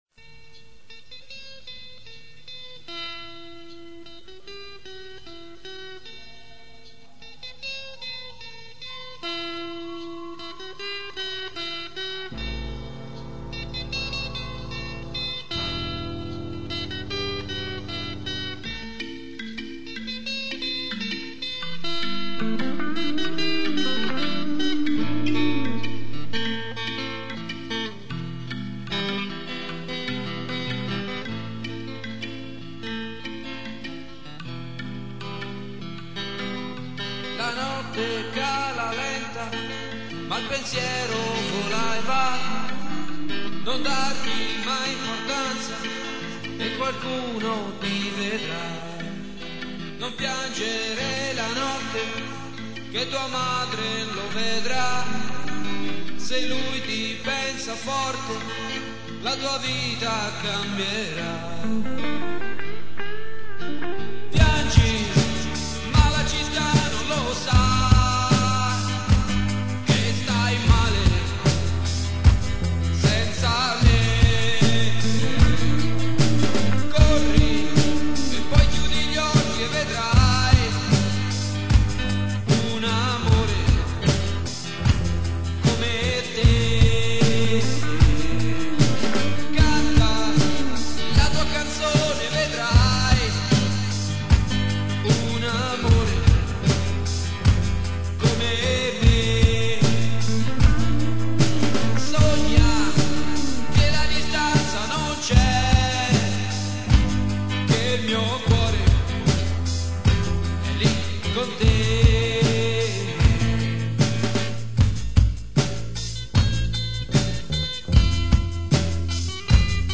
Chitarre
Batteria
Tastiere
Basso
Voce
Cori